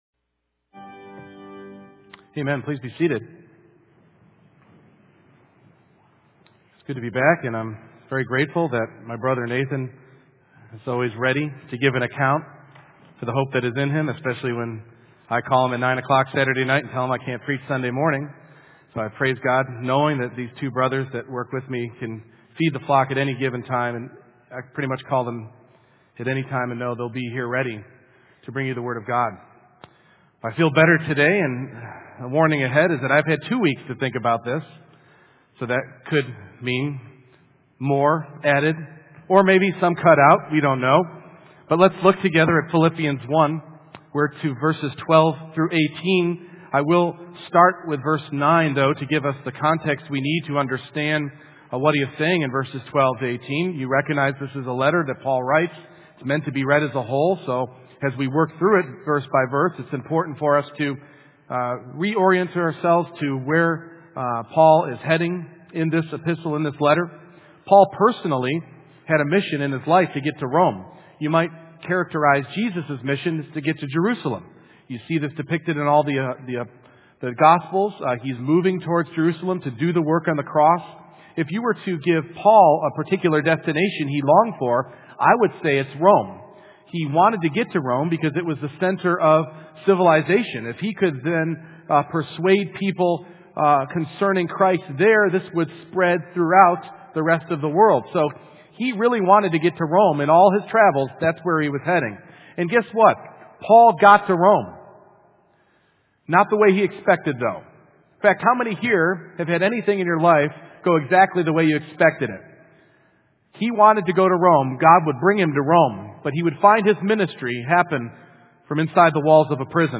Passage: Philippians 1:12-18 Service Type: Morning Worship